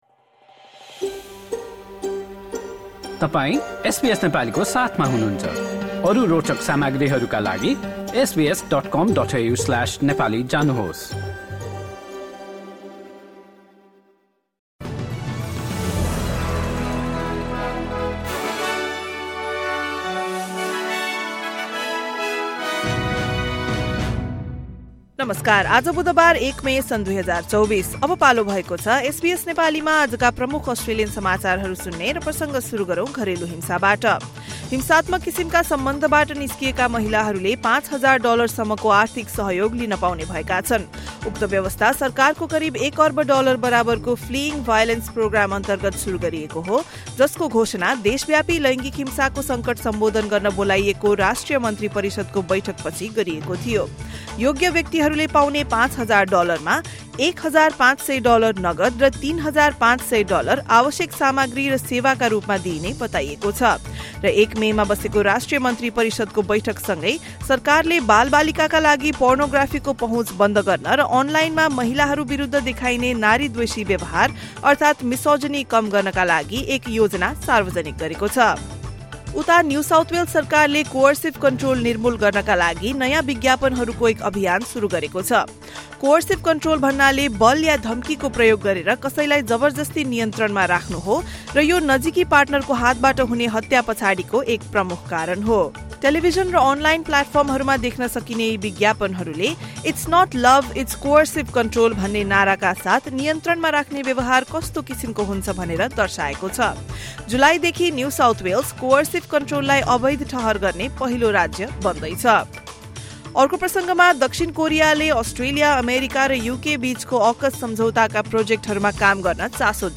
SBS Nepali Australian News Headlines: Wednesday, 1 May 2024